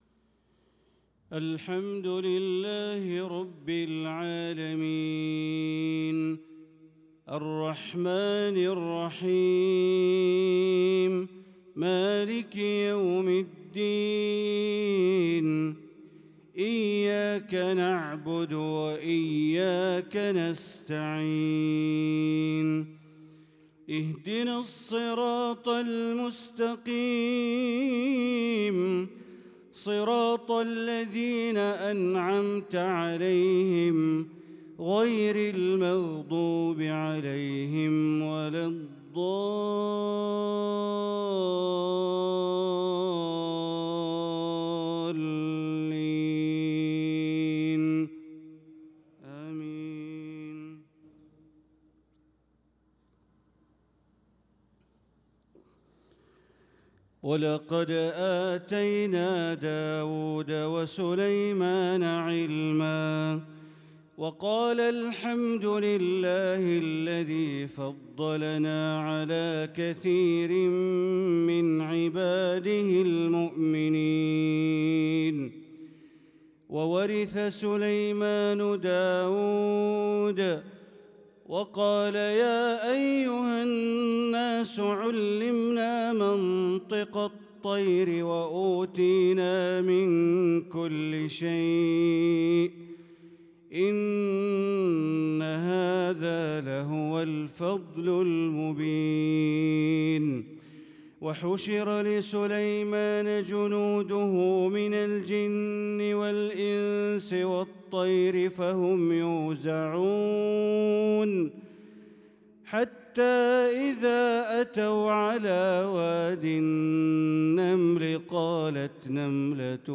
صلاة العشاء للقارئ بندر بليلة 3 ذو القعدة 1445 هـ
تِلَاوَات الْحَرَمَيْن .